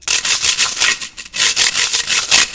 描述：由木材对金属的刮擦而形成。
Tag: MTC500 -M002-S1 木材 MTC500-M002-S13 敲击